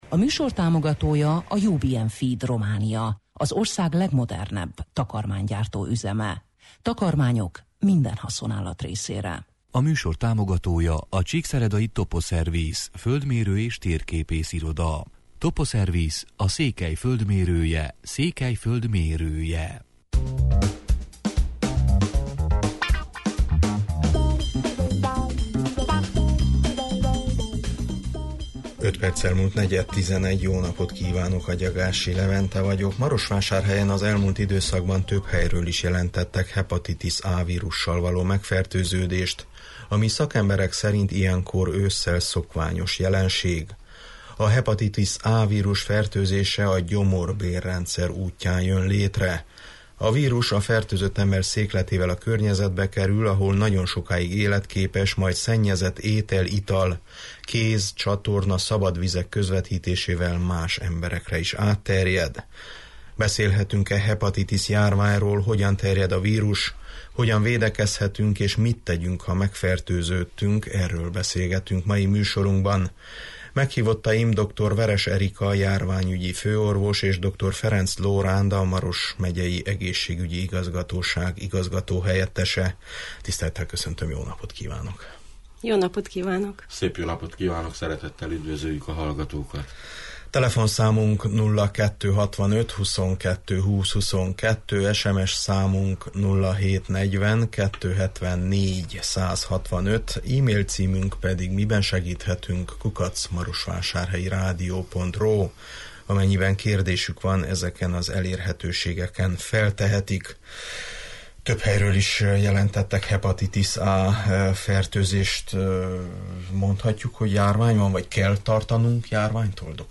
Beszélhetünk-e hepatitis járványról, hogyan terjed a vírus, hogyan védekezhetünk, és mit tegyünk, ha megfertőződtünk – erről beszélgetünk mai műsorunkban.